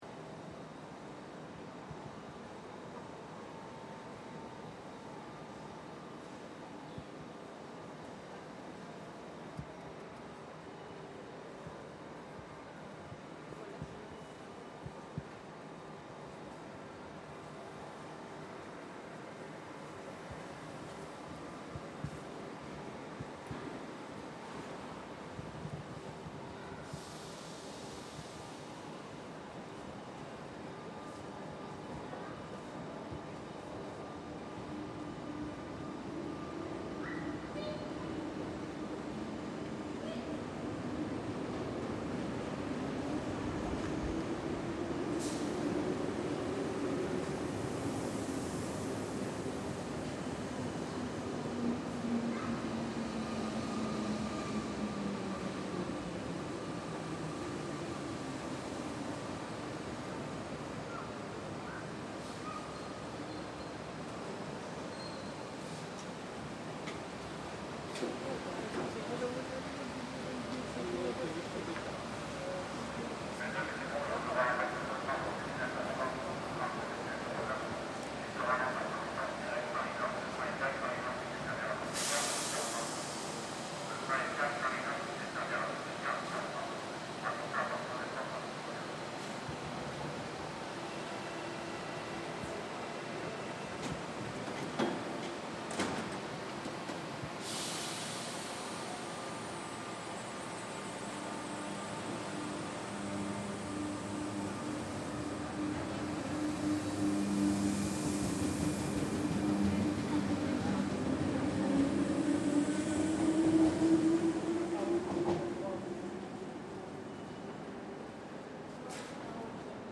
Train leaving Charles de Gaulle